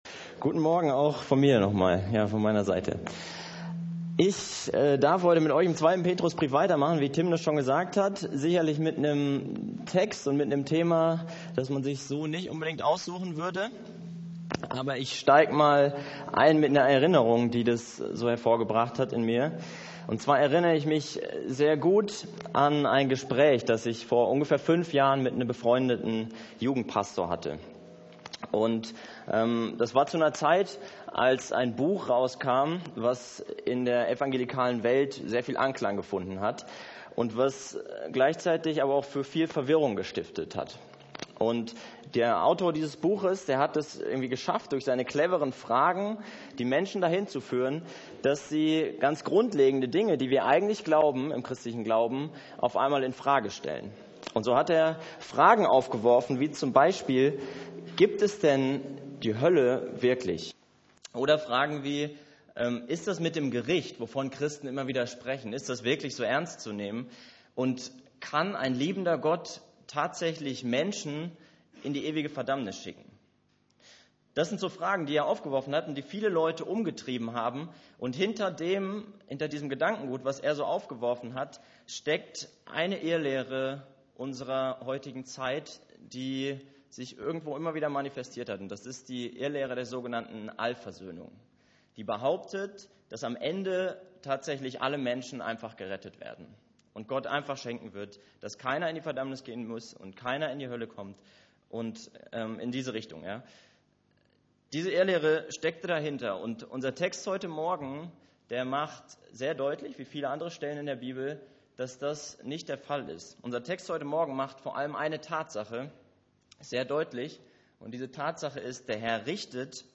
Am Sonntag, den 29. Oktober predigte